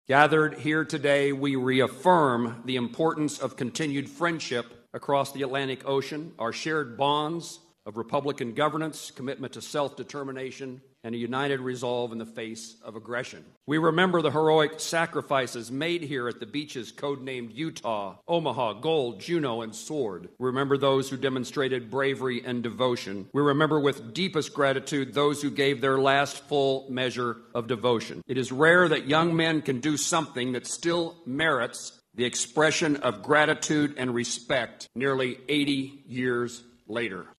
U.S. Sen. Jerry Moran spoke Tuesday at a ceremony in Normandy, France, commemorating the 79th anniversary of D-Day and the Battle of Normandy in World War II.